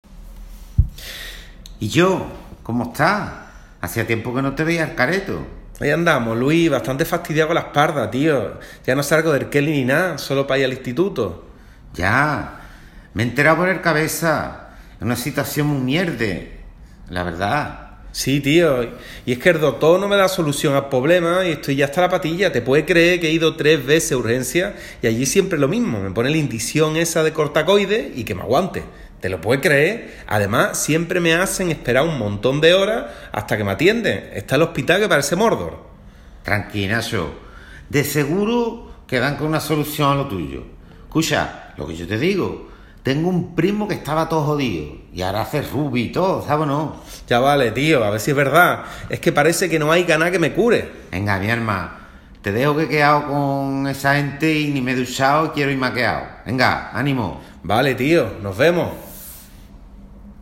Para realizar los ejercicios que se te proponen más abajo, te pedimos que escuches primero este diálogo (haz clic en el reproductor para escucharlo o descárgatelo aquí):
1.1. Indica a qué variedad regional, social y estilística se corresponde la forma de hablar de las dos personas que has escuchado.